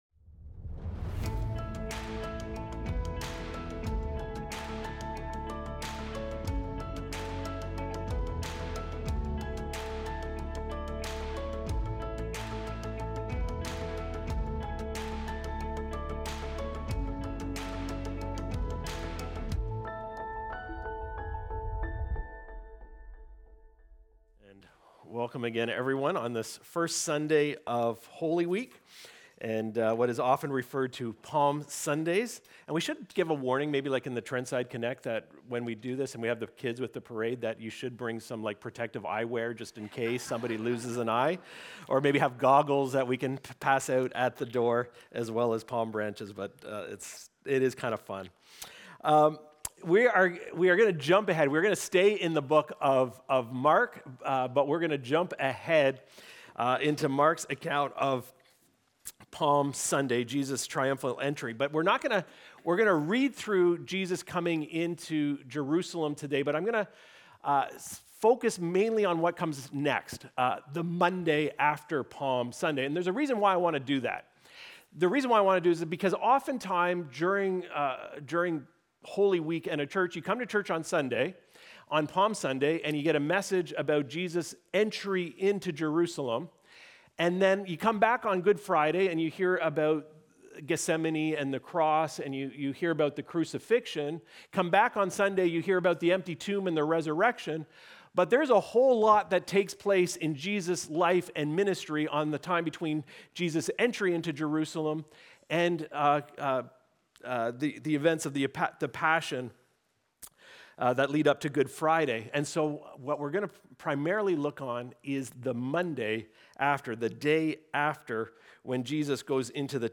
Recorded Sunday, March 29, 2026, at Trentside Bobcaygeon.